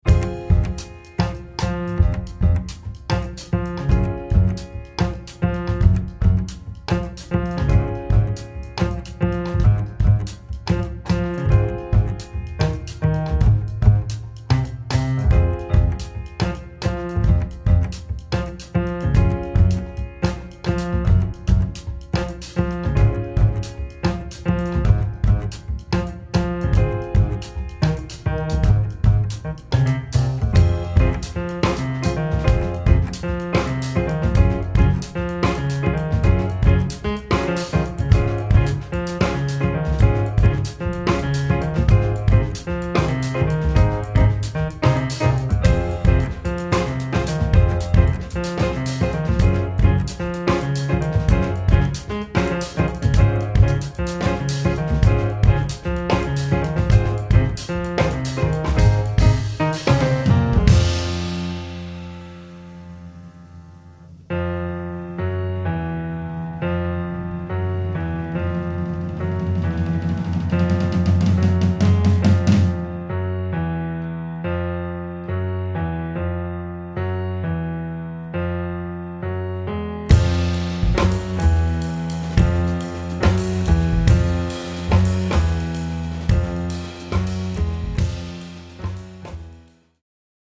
db